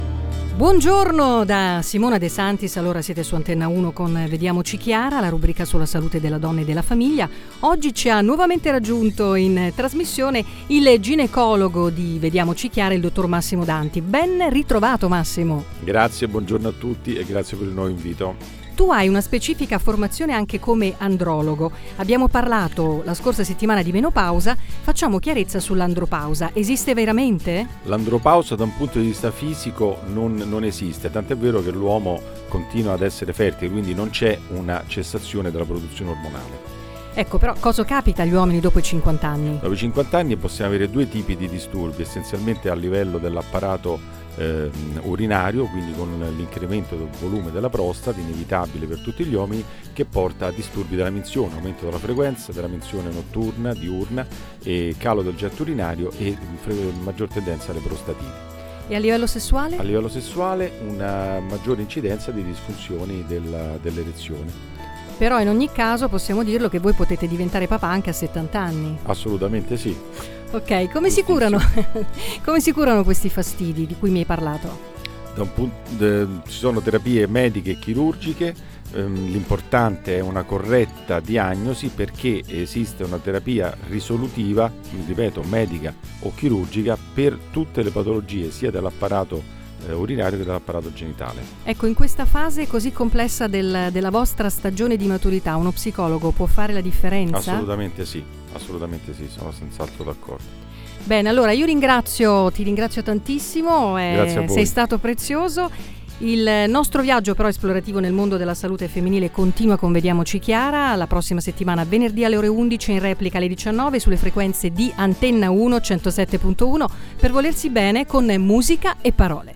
INTERVISTA ANTENNA1